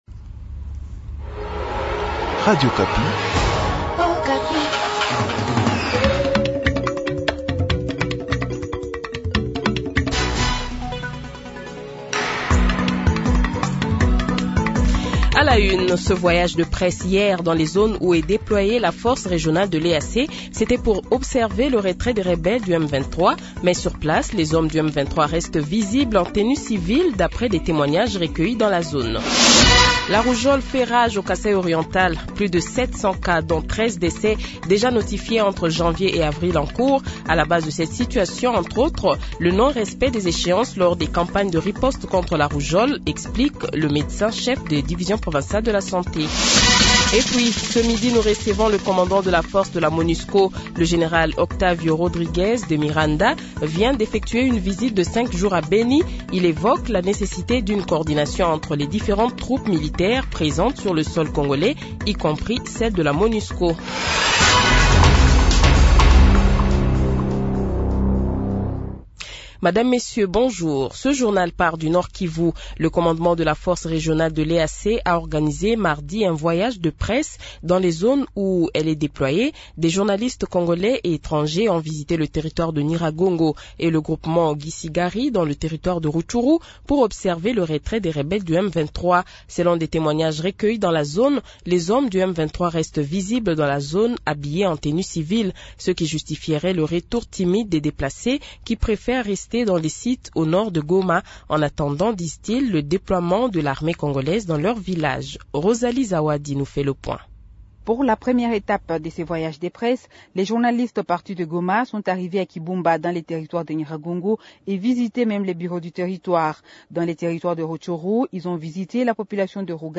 Le Journal de 12h, 19 Avril 2023 :